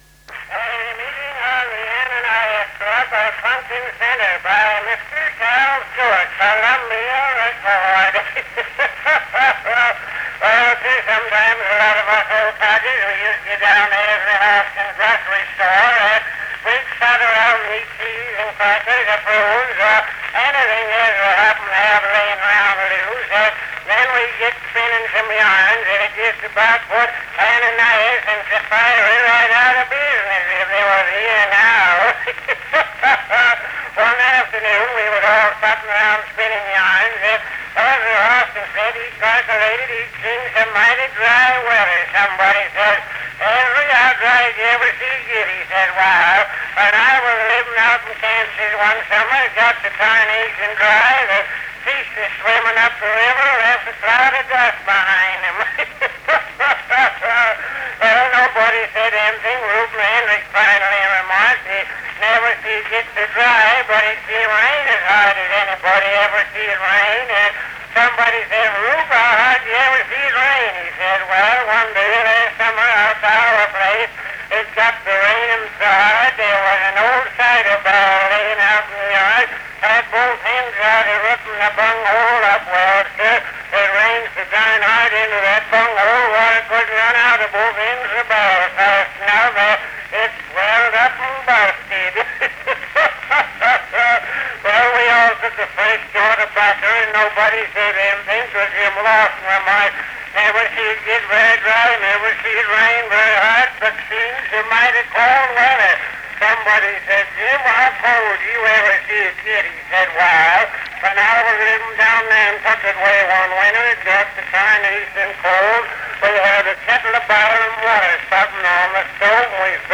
Cal Stewart's comedy routine, Meeting of the Aninias Club at Punkin Center.